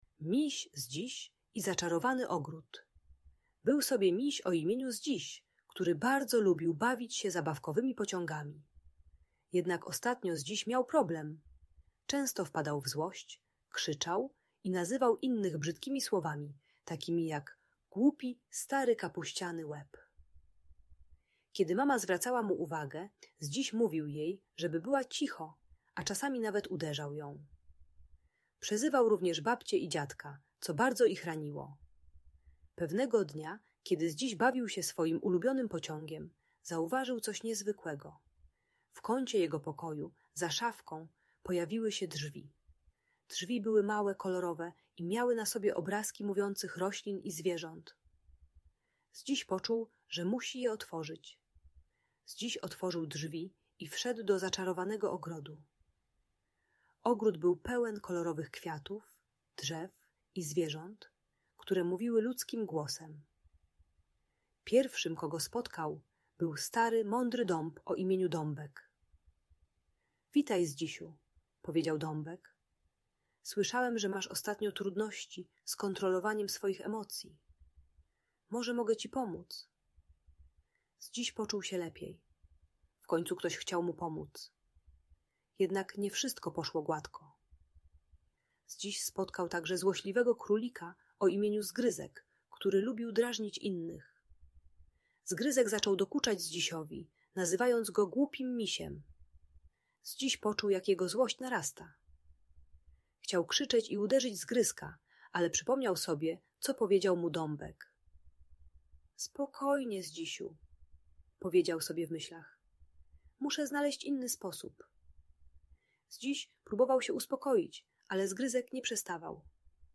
Miś Zdziś i Zaczarowany Ogród - Audiobajka dla dzieci